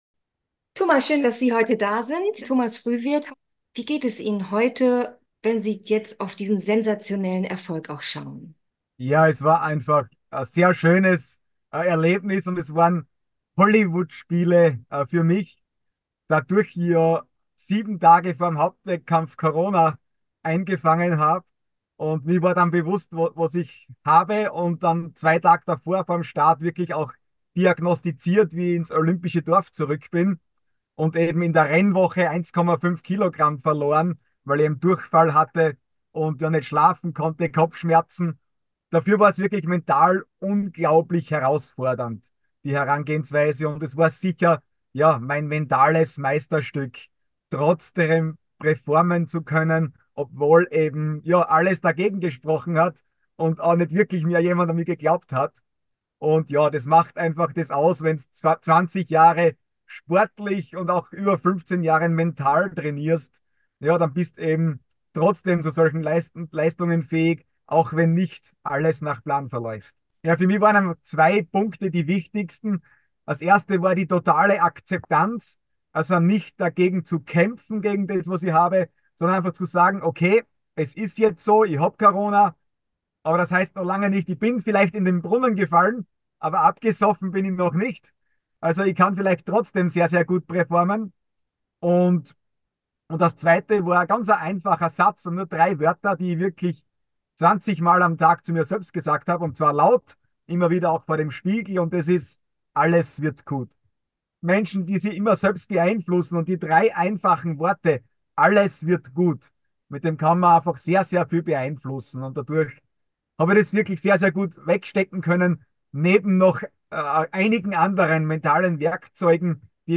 Regeneration als Erfolgsfaktor im Sport – Interview mit Para-Sportler Thomas Frühwirth | BioRelax® ➤ Jetzt lesen